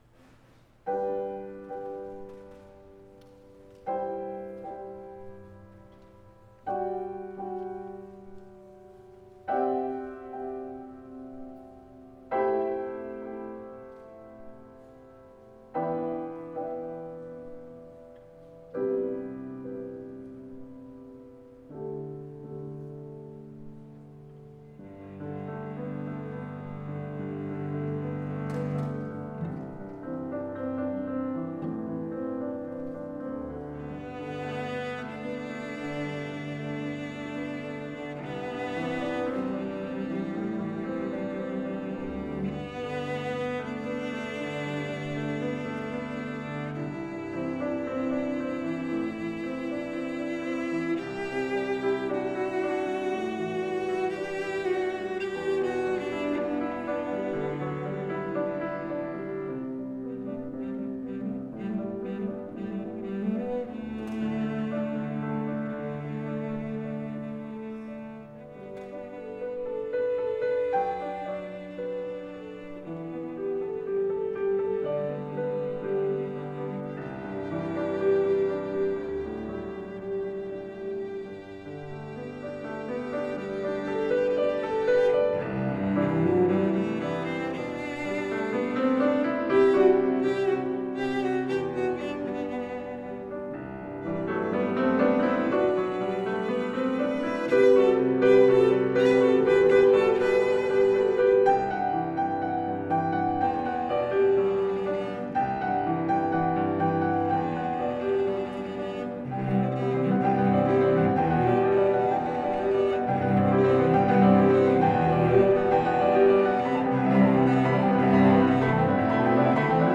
for Viola, Cello, and Piano (2021)
viola
cello
piano.